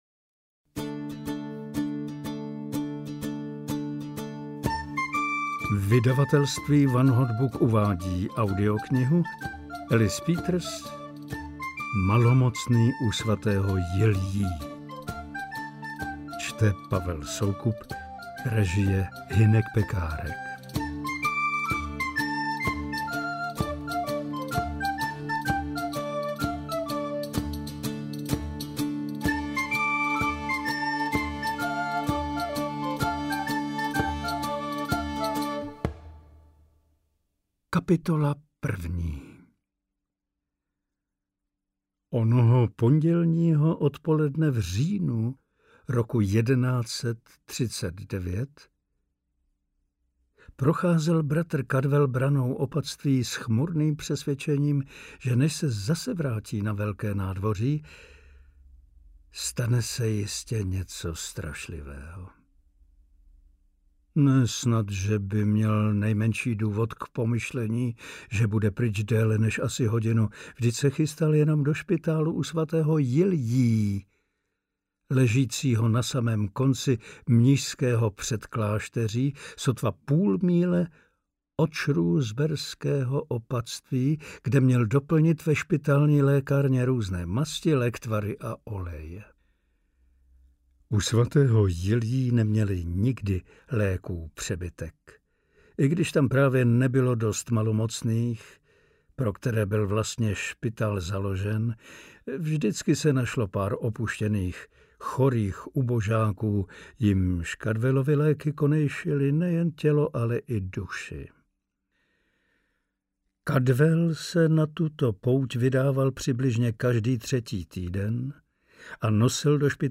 Interpret:  Pavel Soukup
AudioKniha ke stažení, 30 x mp3, délka 10 hod., velikost 539,0 MB, česky